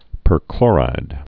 (pər-klôrīd) also per·chlo·rid (-klôrĭd)